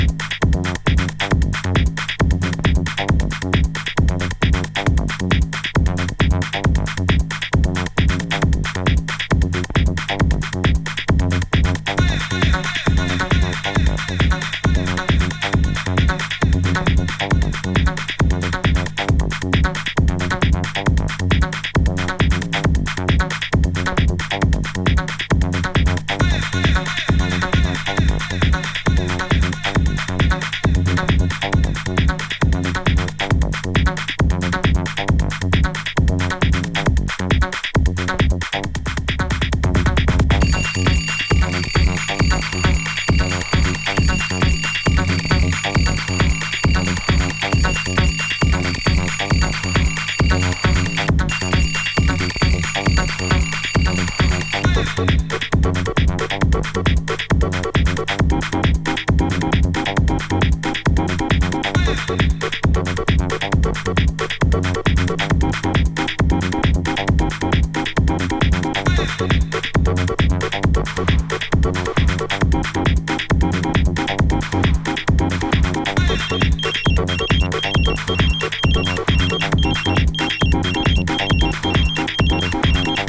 シンプルだけど、力強く＆捻りの効いたテクノ。